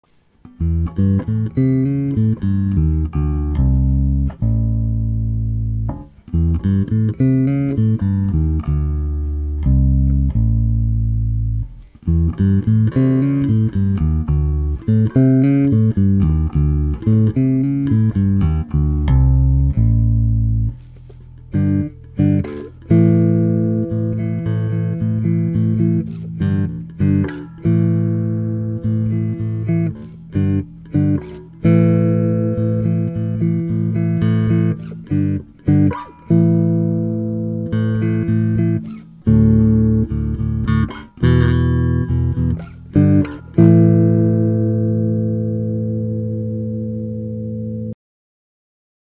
The bridge pickup is aggressive with very strong nasal overtones. Turn the blend pot towards the bridge and hear the midrange coming in.
Listen here:     Bridge